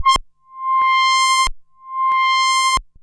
Roland A C6.wav